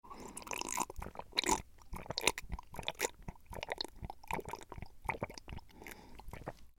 دانلود آهنگ آب 5 از افکت صوتی طبیعت و محیط
جلوه های صوتی
دانلود صدای آب 5 از ساعد نیوز با لینک مستقیم و کیفیت بالا